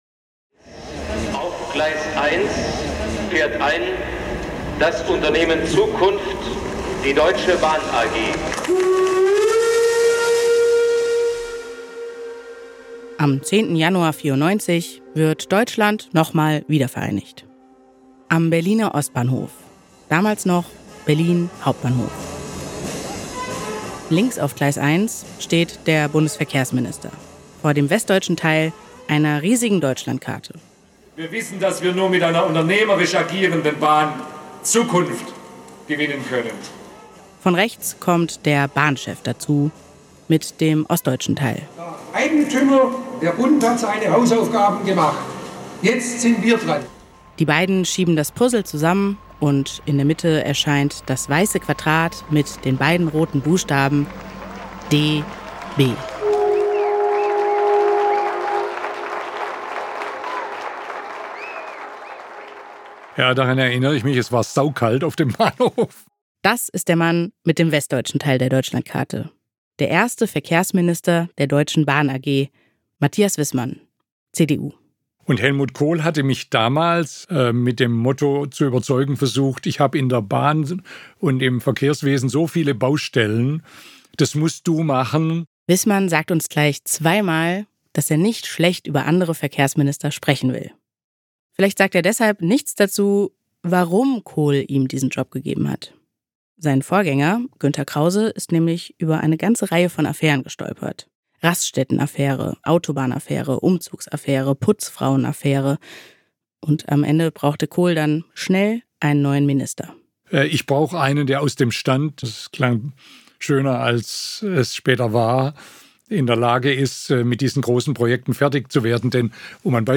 Matthias Wissmann ist damals Bundesverkehrsminister und erinnert sich an das große Versprechen.